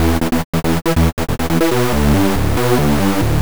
Index of /musicradar/future-rave-samples/140bpm
FR_MoHoov_140-E.wav